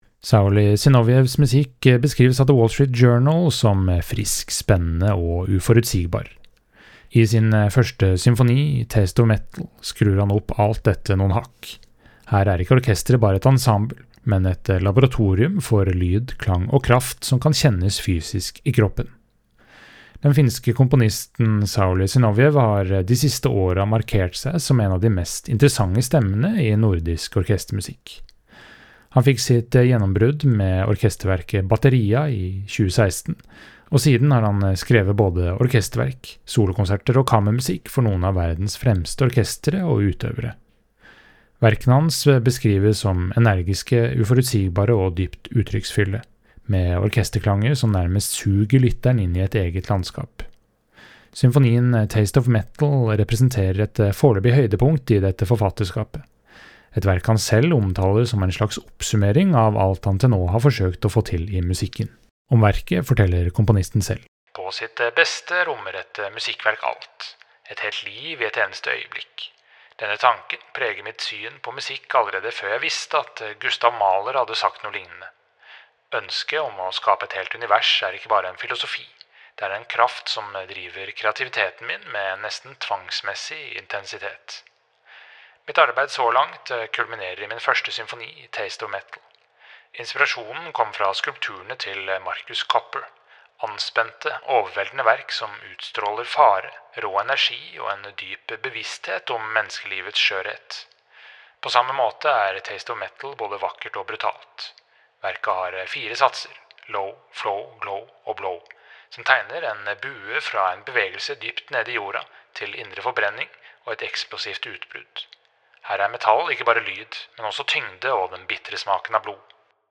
VERKOMTALE